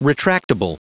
Prononciation du mot retractable en anglais (fichier audio)
Prononciation du mot : retractable